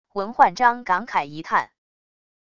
闻焕章感慨一叹wav音频